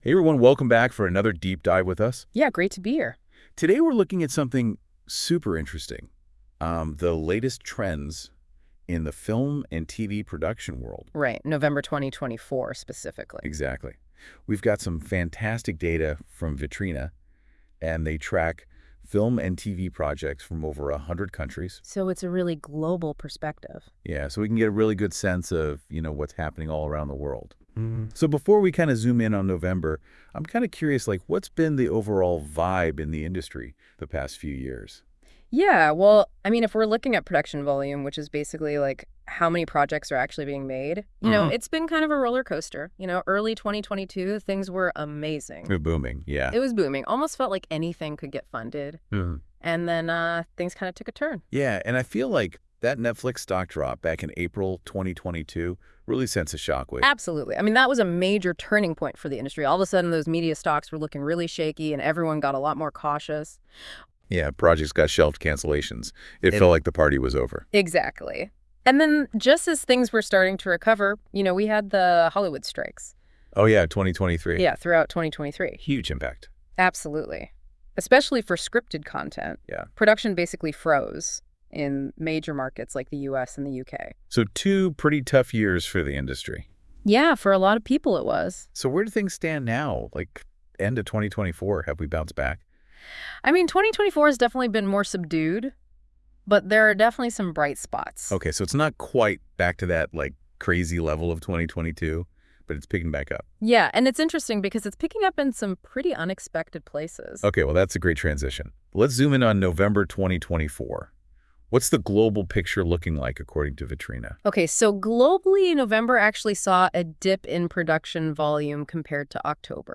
The audio podcast was generated with Deep Dive and reviewed by our team.